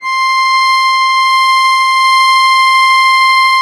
MUSETTESW.17.wav